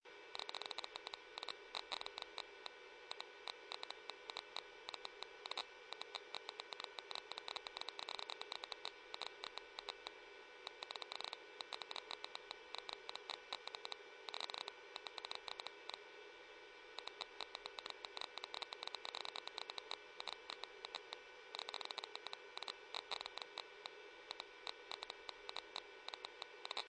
radiation_ticking.ogg